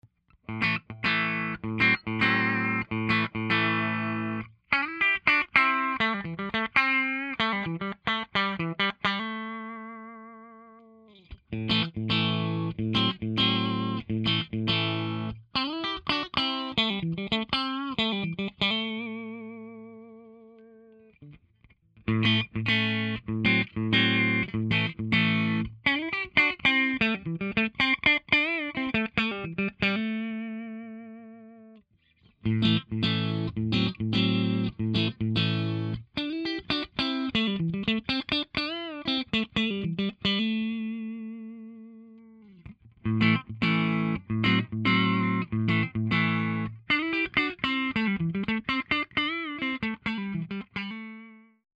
Pickup Selector Positions 1, 2, 3,4 & 5
- 1969 Fender Strat w/Alder Body & Rosewood Fingerboard
- Beckemer Solid State Amp
CBS Style Strat Pickups - Clean
StratClean5.mp3